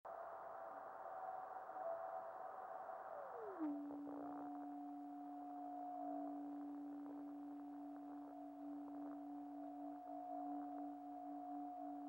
One channel of sound only.
Radio spectrogram of the time of the above meteor.  61.250 MHz reception above white line, 83.250 MHz below white line.